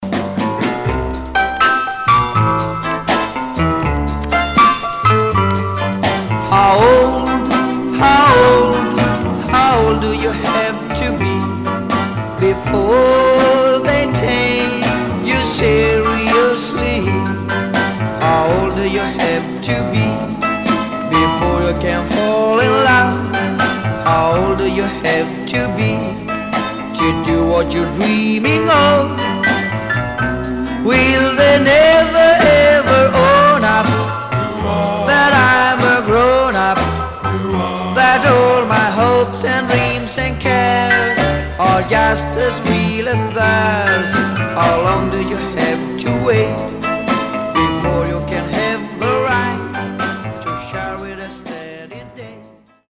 Surface noise/wear
Mono
Teen